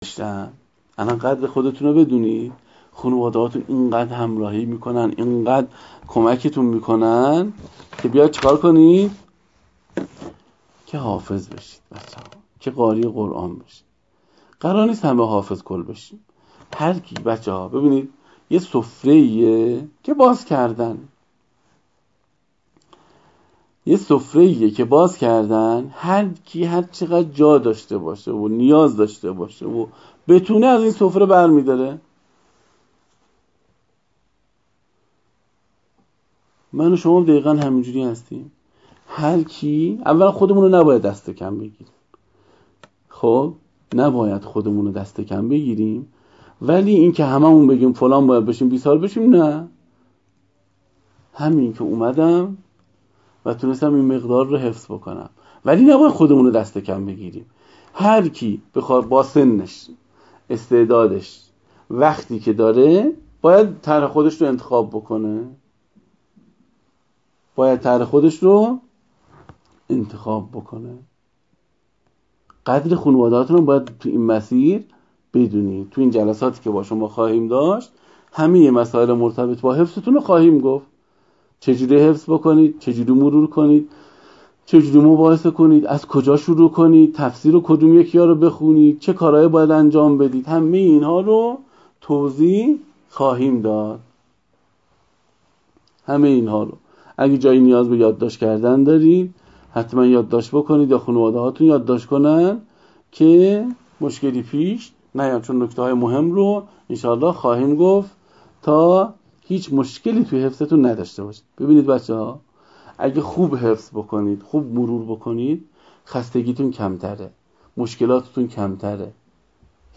حافظ و مدرس دوره‌های تربیت مربی حفظ قرآن با اشاره به شیوه حفظ در مقاطع خردسال و نوجوان گفت: قرار نیست تمامی افرادی که به این عرصه ورود می‌کنند حافظ کل شوند، بلکه حفظ قرآن سفره‌ای است که هر فردی به اندازه نیازش از آن بهره‌مند و متنعم می‌شود.